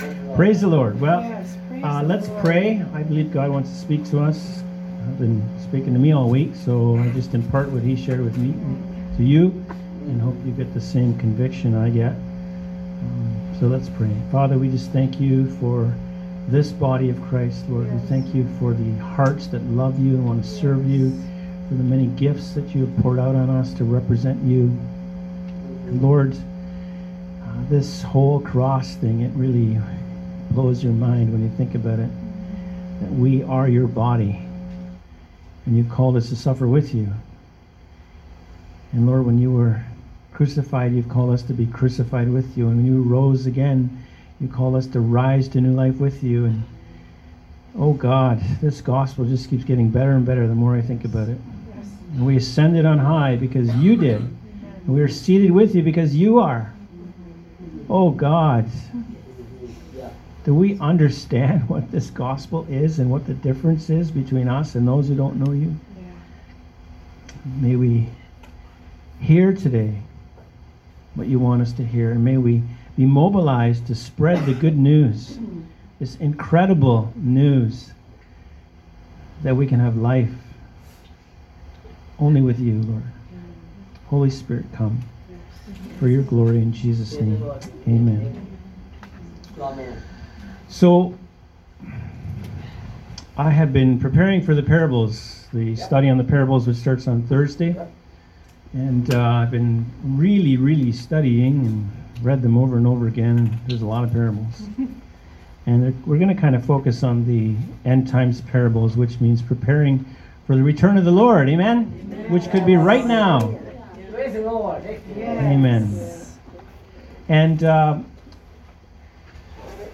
Audio Sermons - Freedom House Church and Healing Centre